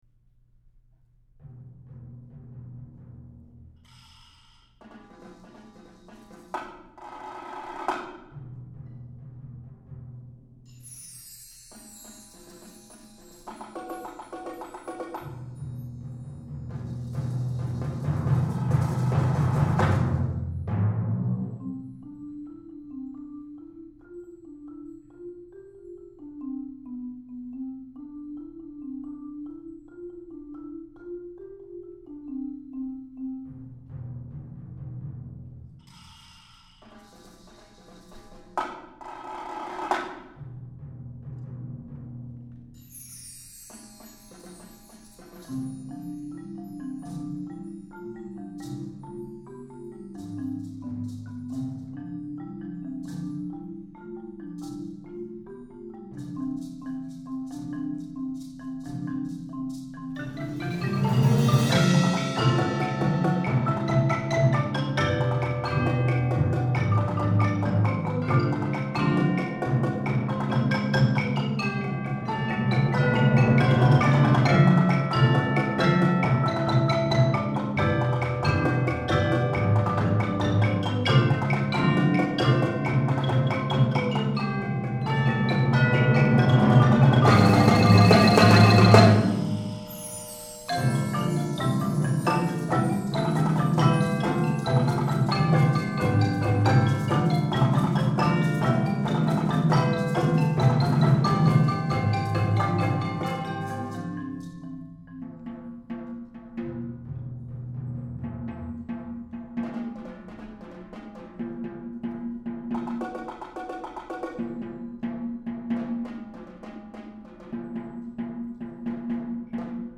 Voicing: Percussion Octet